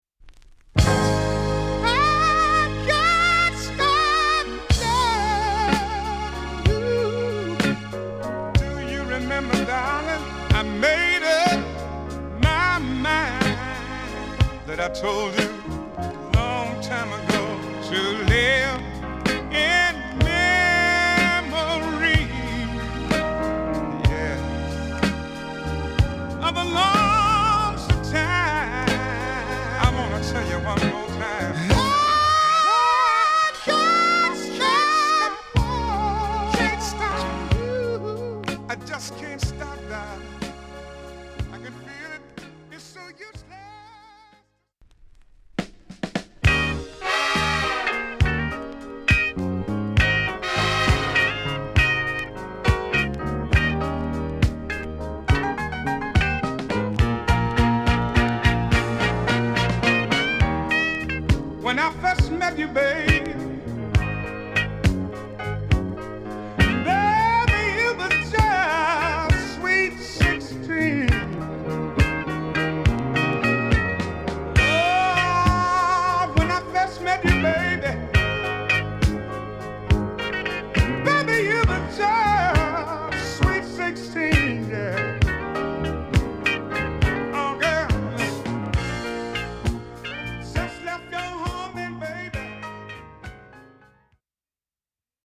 New Orleans R&B 45.
Record: VG+ (light surface marks, clean copy)